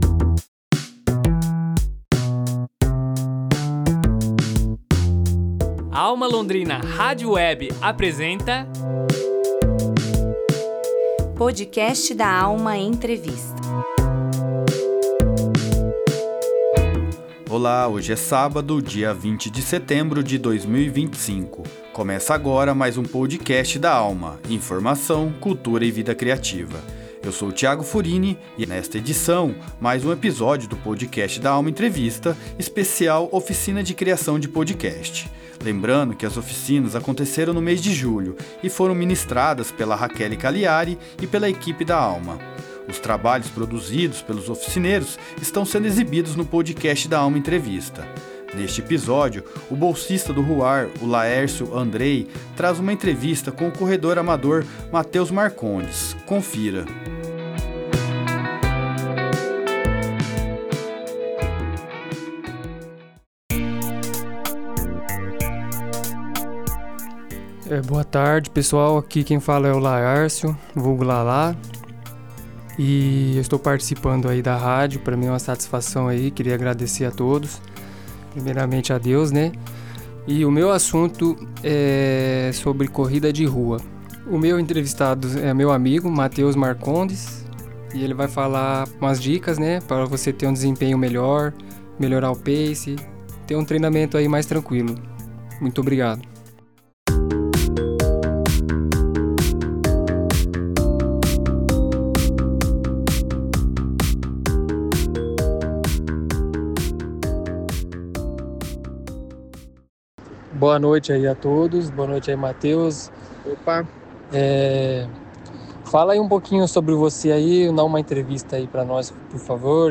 uma entrevista especial